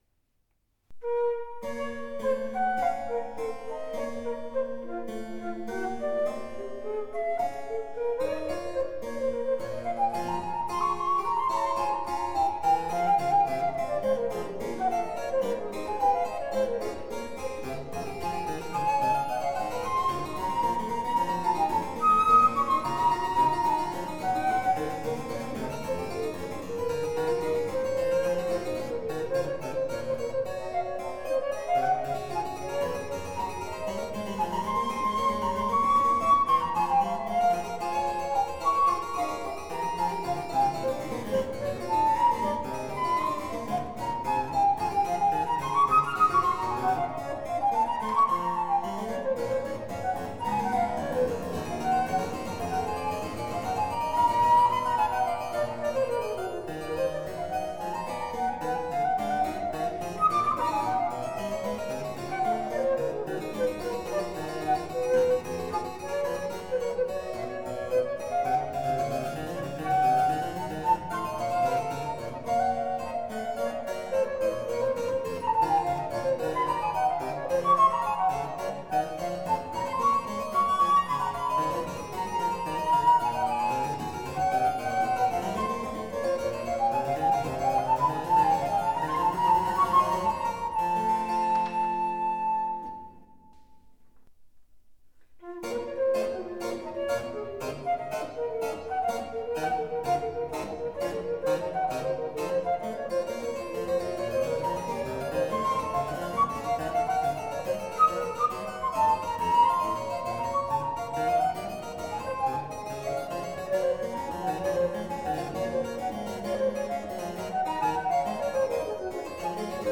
Sonate h-Moll BMW 1030 für Flöte und Cembalo